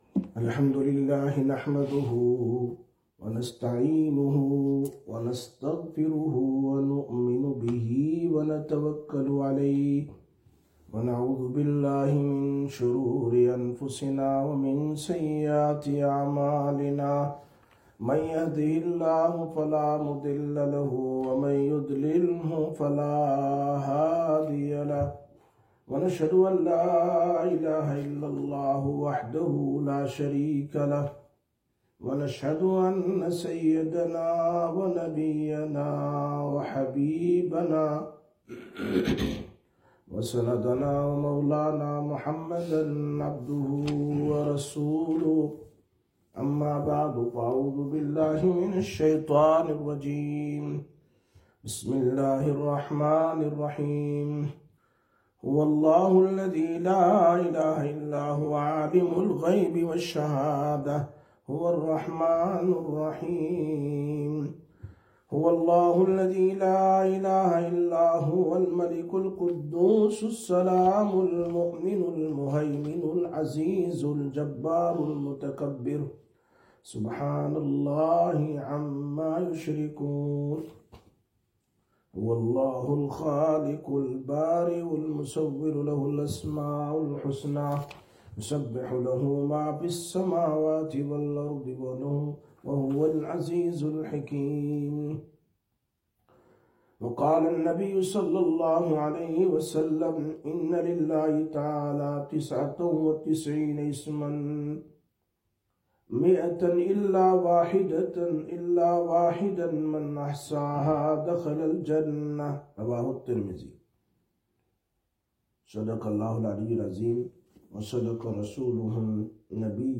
09/04/2025 Sisters Bayan, Masjid Quba